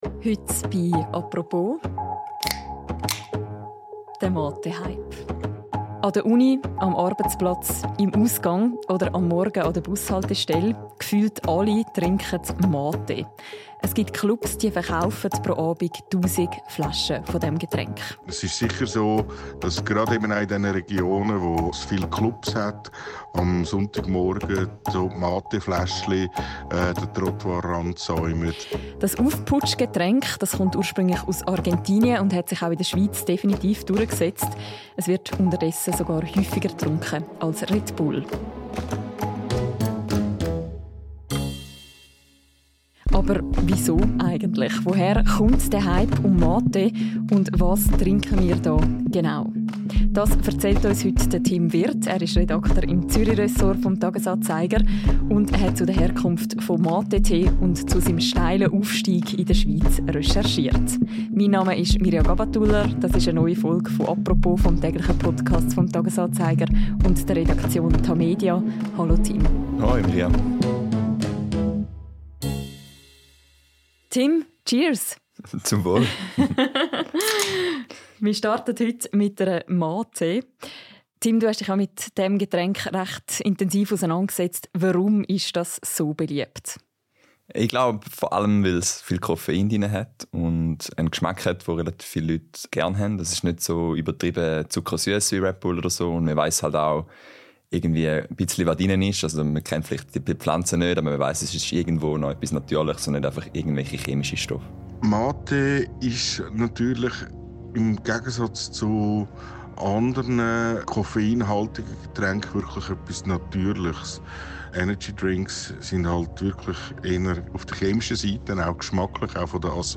Er ist zu Gast in einer neuen Folge des täglichen Podcasts «Apropos».